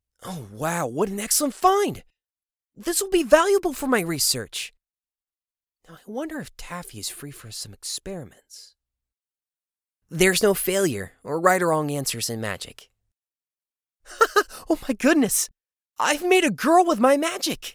Voice: Upbeat, excited, higher pitched-range